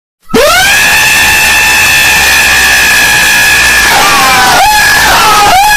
Bird Laughing D